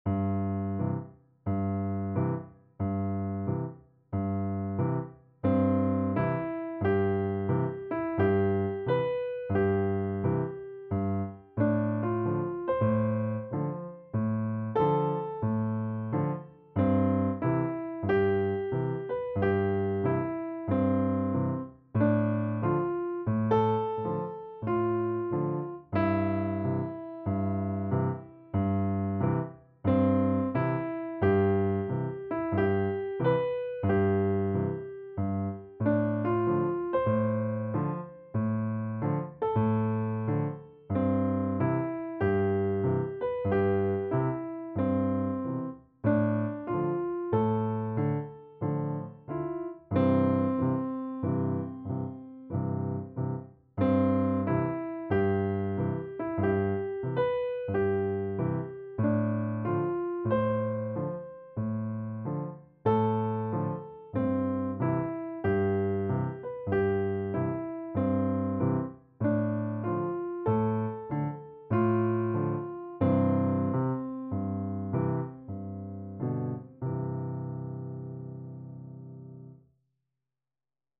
6 instrumental